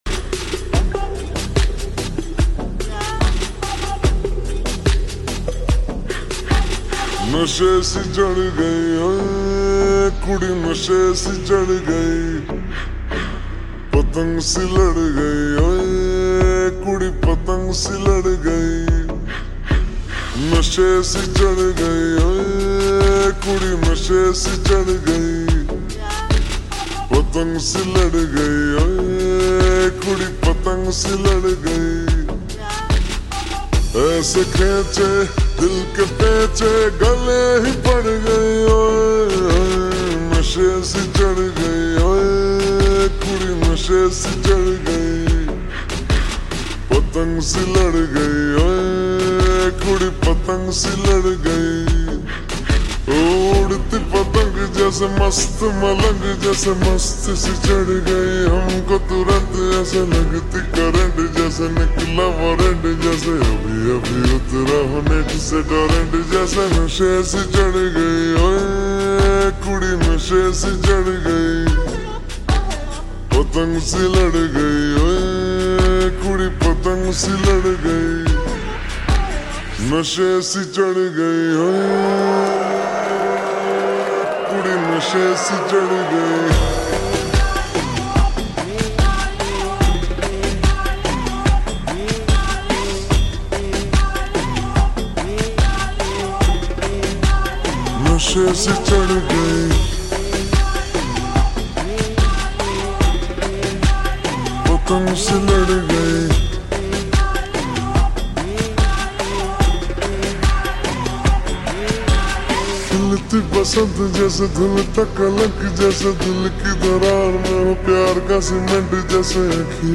[Slowed+Reverb]